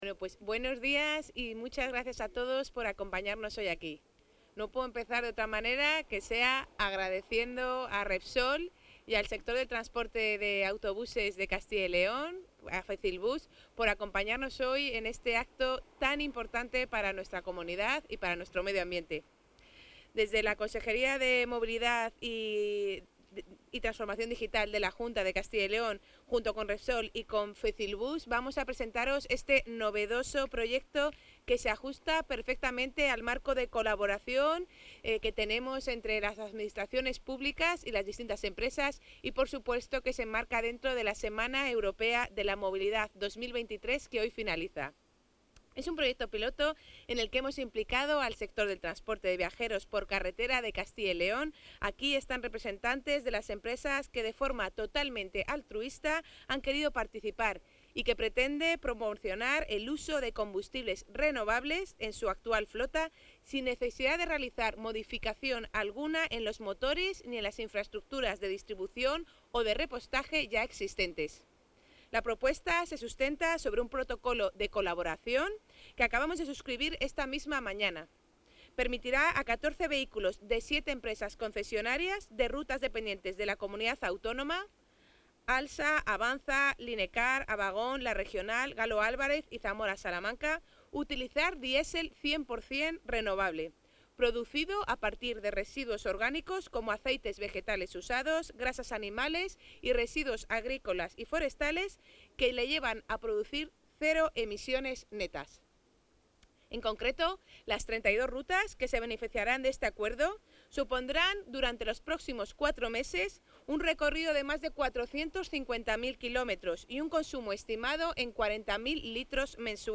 Intervención de la consejera de Movilidad y Transformación Digital.